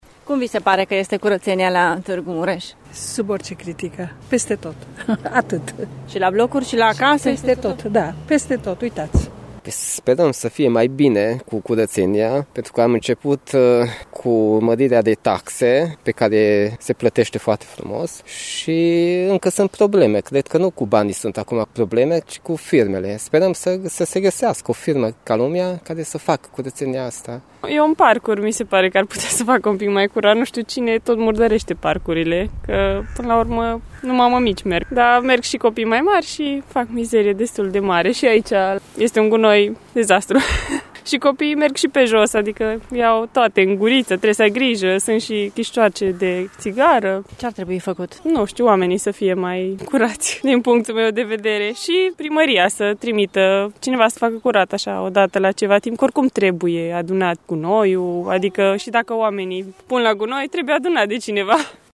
Problema este, însă, una complexă, spun aceștia: